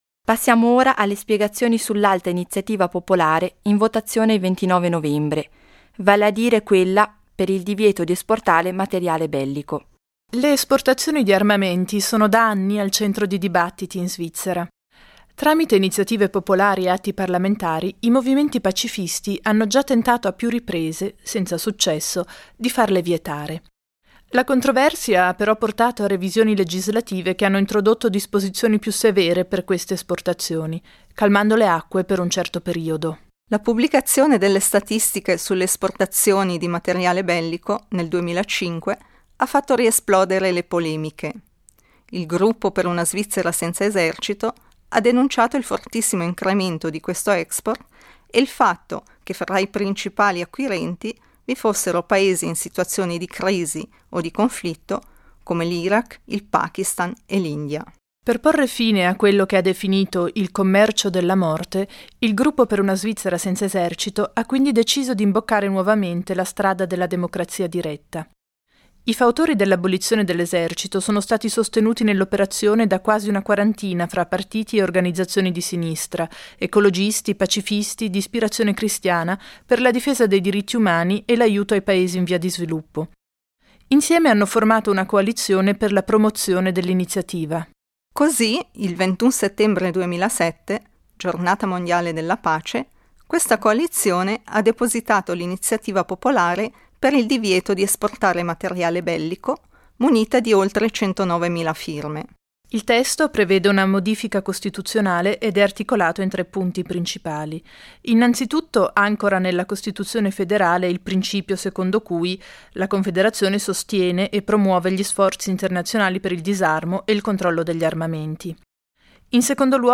Gli argomenti a favore sono esposti dal consigliere nazionale Verde Josef Lang, mentre quelli contrari sono illustrati dal consigliere agli Stati popolare democratico Bruno Frick.